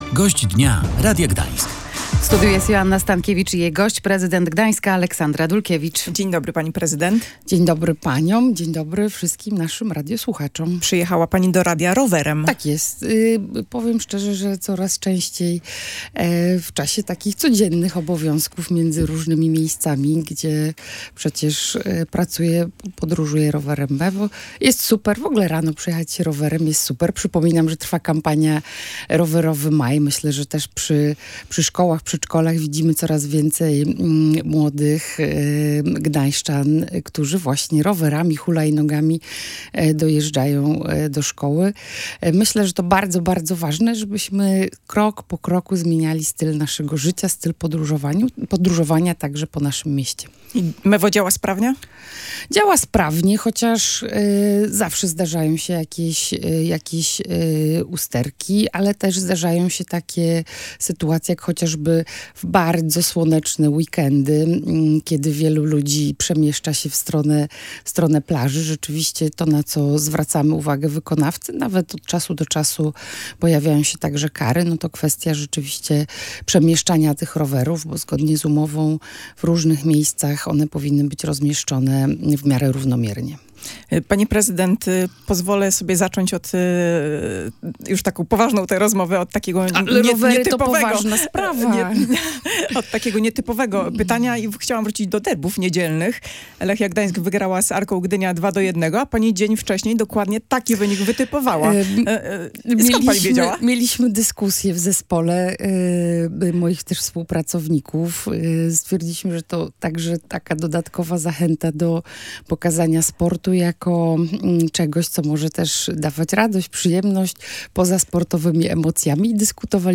Dlatego w Urzędzie Miejskim w Gdańsku pojawia się dyrektor generalny ds. zieleni – mówiła w Radiu Gdańsk Aleksandra Dulkiewicz, prezydent Gdańska.